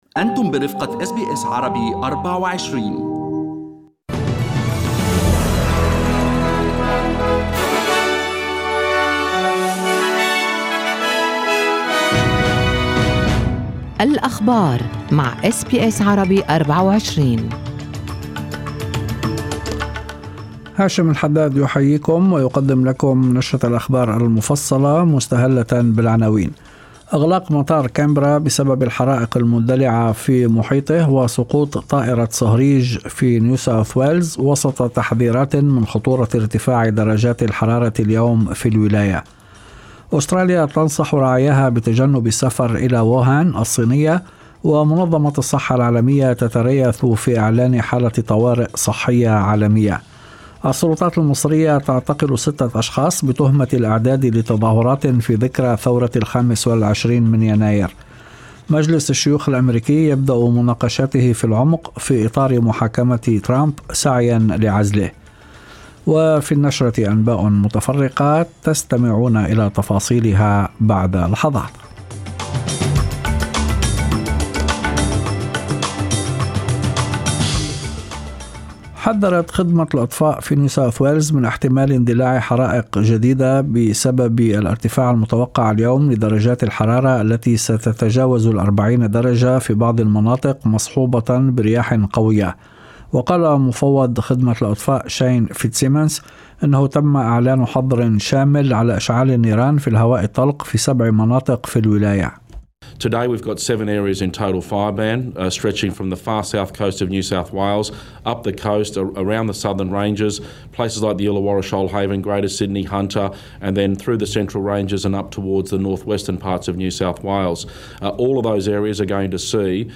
نشرة أخبار المساء 23/01/2020
Arabic News Bulletin Source: SBS Arabic24